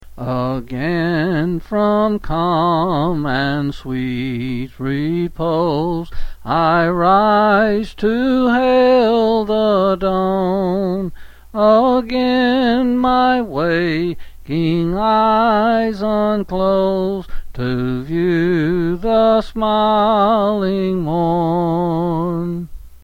Quill Selected Hymn
C. M.